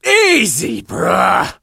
monster_stu_hurt_vo_02.ogg